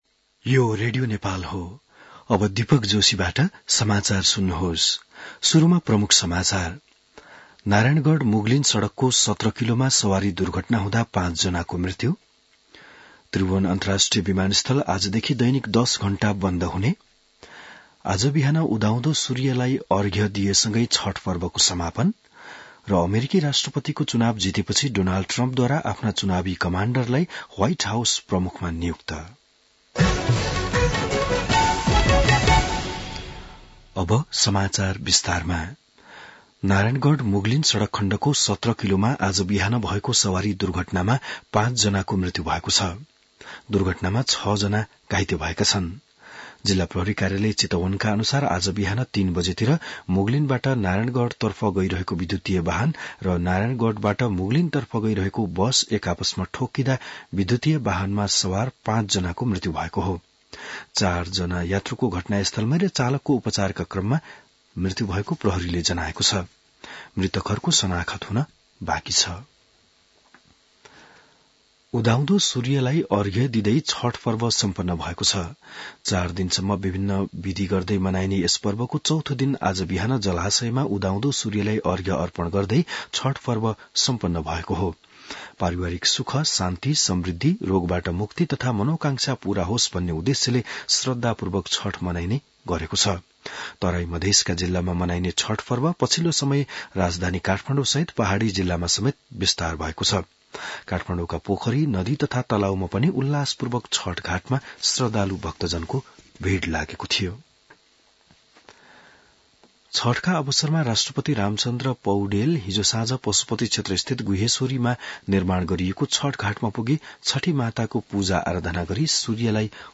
बिहान ९ बजेको नेपाली समाचार : २४ कार्तिक , २०८१